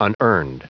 Prononciation du mot unearned en anglais (fichier audio)
Prononciation du mot : unearned